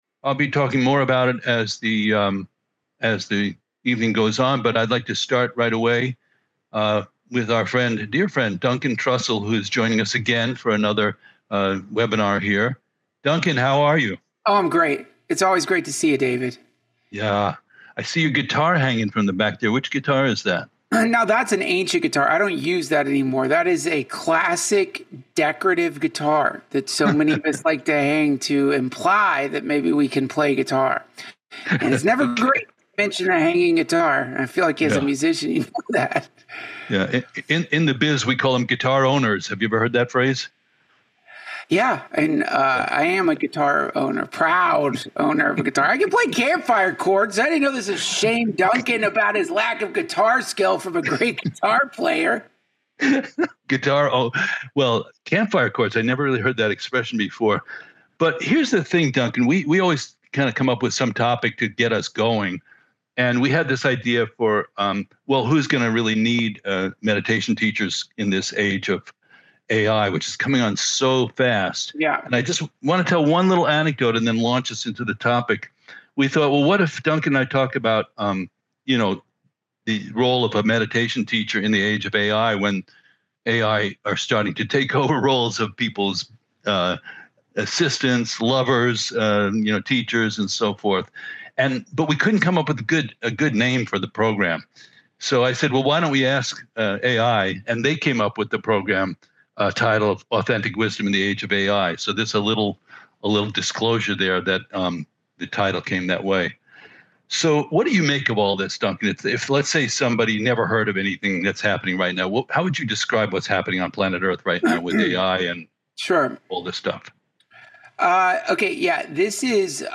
A DTFH Special Event!